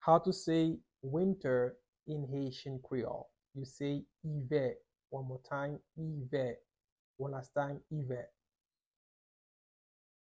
Pronunciation:
27.How-to-say-Winter-in-Haitian-creole-ive-with-pronunciation.mp3